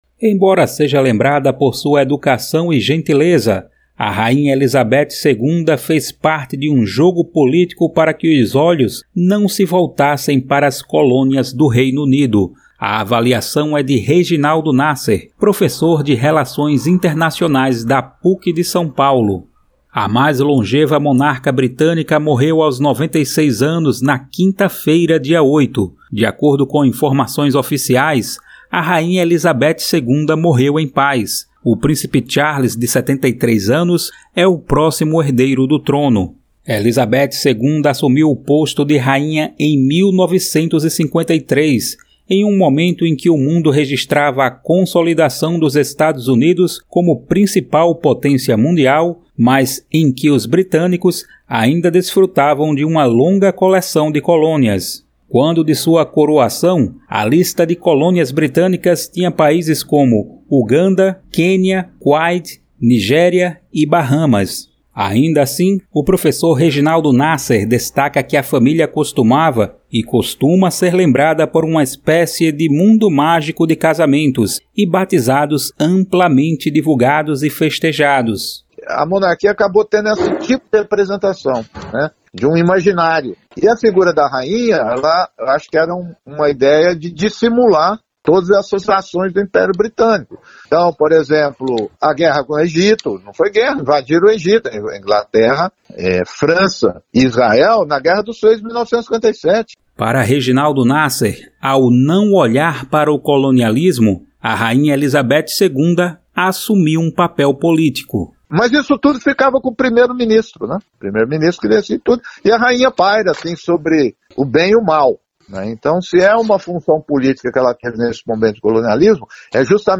ENTREVISTA: Rainha Elizabeth 2ª serviu para “dissimular” colonialismo britânico, diz pesquisador